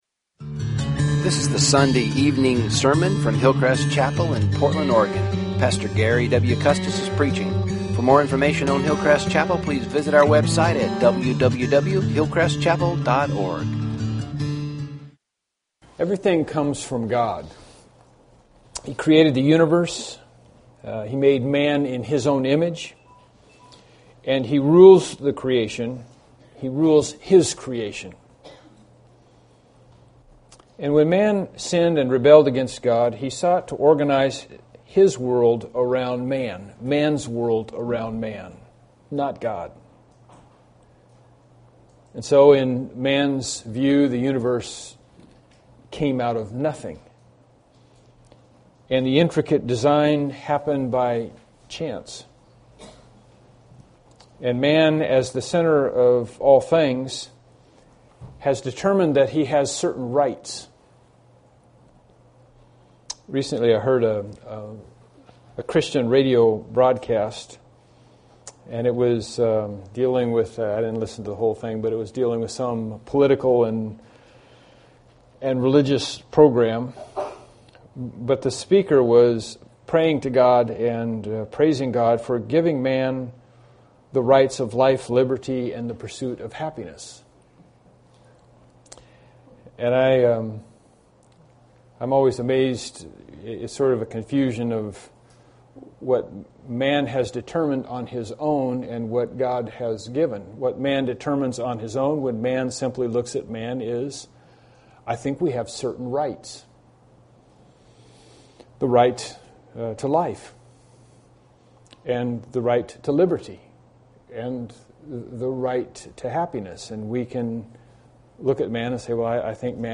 Bible Text: John 1:11-13 | Preacher